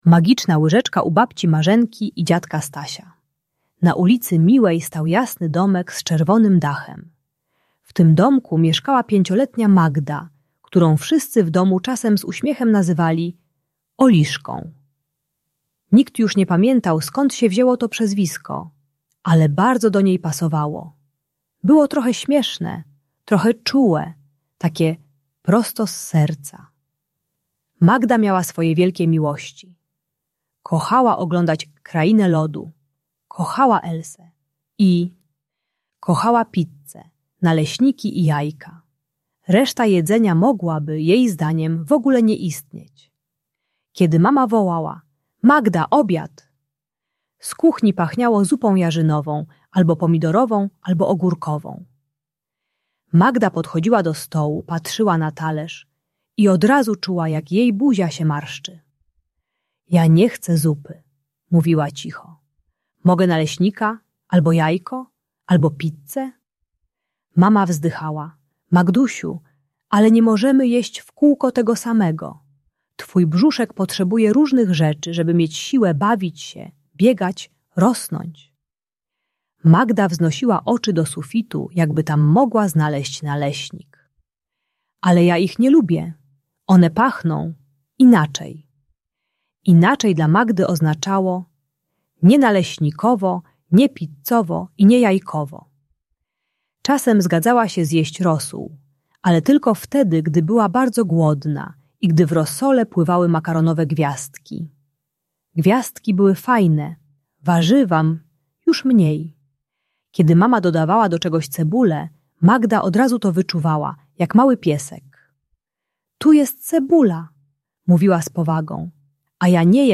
Ta audiobajka o problemach z jedzeniem u przedszkolaka uczy techniki małych kroków - "kropelka po kropelce" - bez zmuszania do jedzenia. Pomaga dziecku oswoić się z nowymi smakami przez zabawę i wyobraźnię.